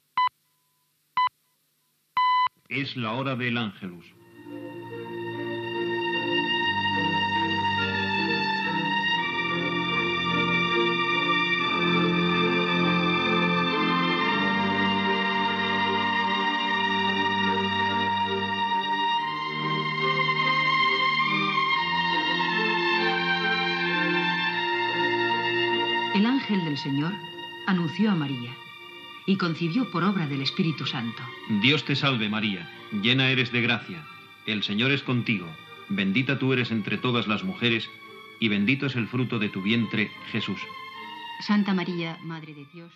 Senyals horaris i inici de l'oració mariana